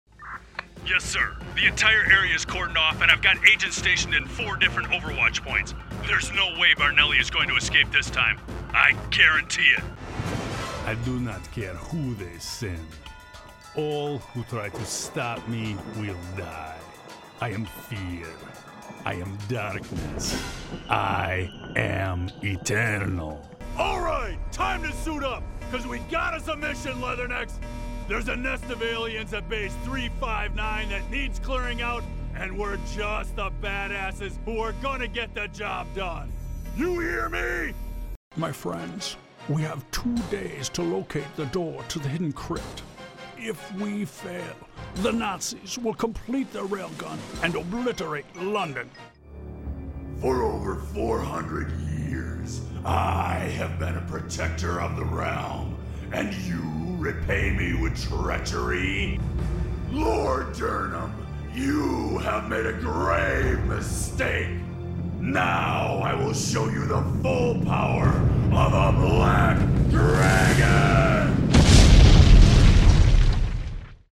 He would gladly lend his rich, deep voice to your project.
Mature Adult, Adult, Young Adult Has Own Studio
Location: Watertown, WI, USA Languages: english 123 Accents: standard us Voice Filters: VOICEOVER GENRE commercial explainer video gaming real estate NARRATION FILTERS character